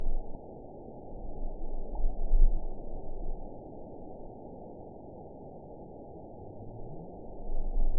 event 912357 date 03/25/22 time 10:04:16 GMT (3 years, 9 months ago) score 8.73 location TSS-AB03 detected by nrw target species NRW annotations +NRW Spectrogram: Frequency (kHz) vs. Time (s) audio not available .wav